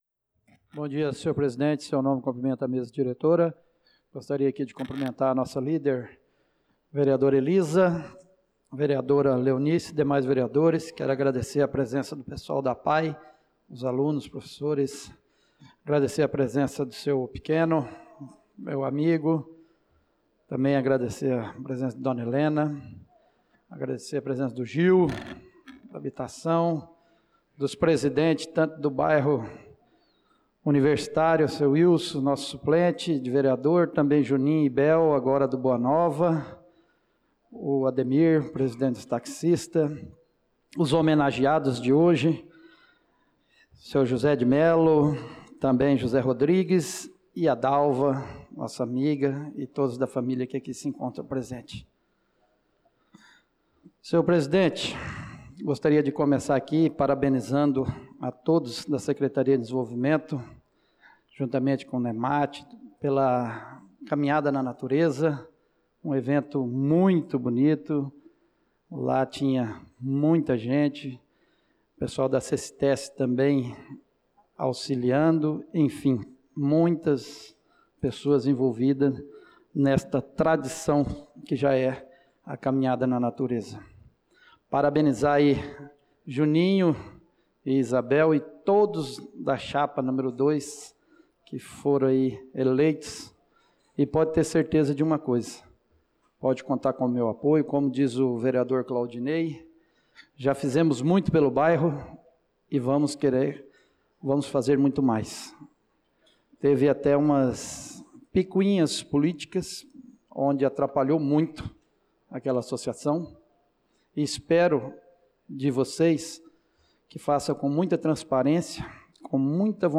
Pronunciamento do vereador Marcos Menin na Sessão Ordinária do dia 26/05/2025